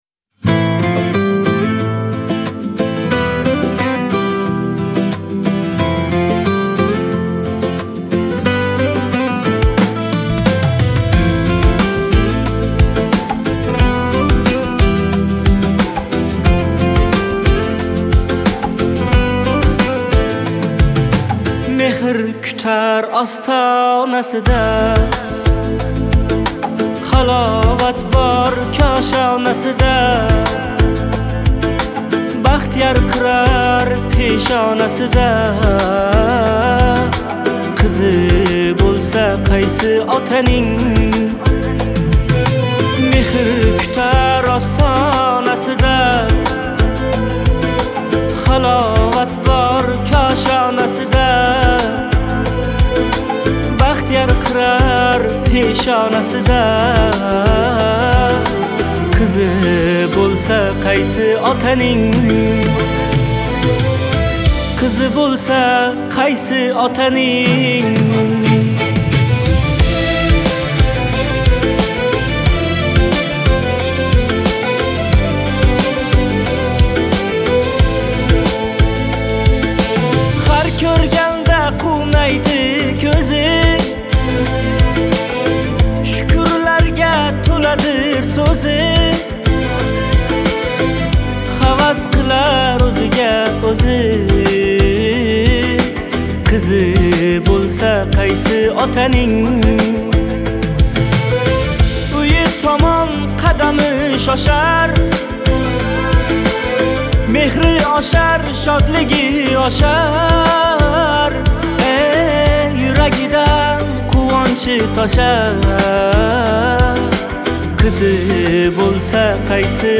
Трек размещён в разделе Узбекская музыка / Поп.